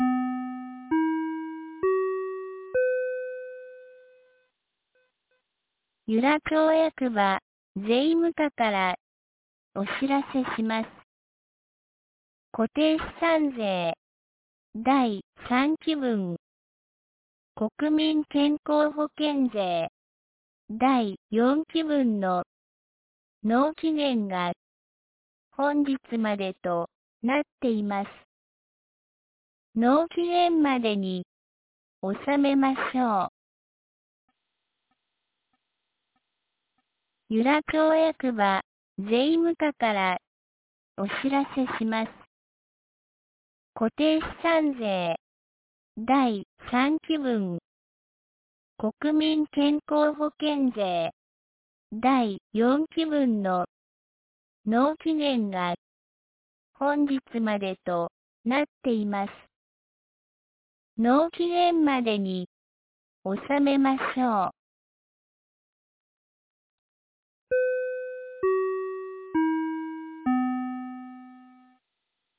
2025年09月30日 07時51分に、由良町から全地区へ放送がありました。